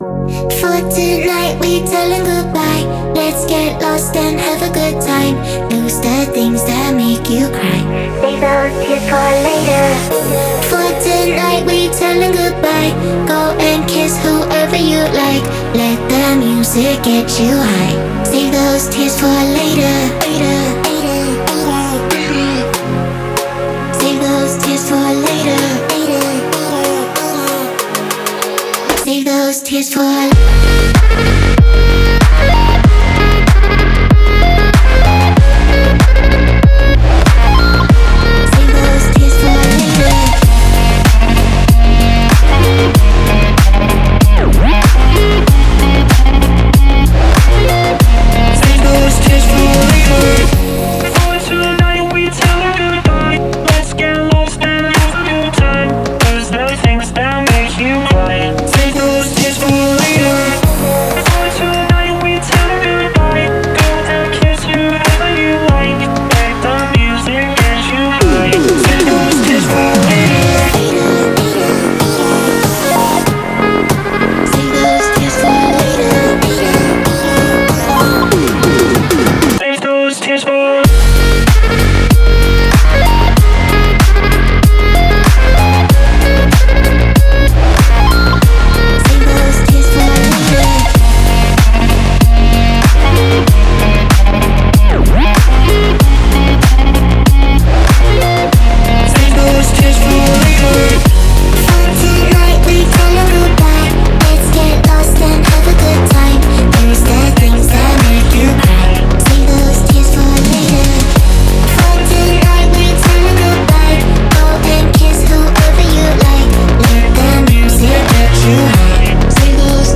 это яркий трек в жанре электронной танцевальной музыки